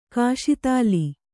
♪ kāśi tāli